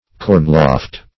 Cornloft \Corn"loft`\ (k?rn"l?ft`), n. A loft for corn; a granary.